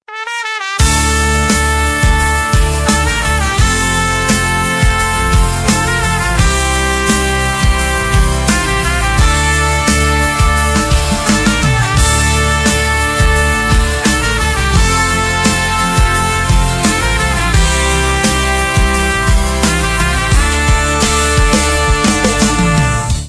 без слов , alternative rock
труба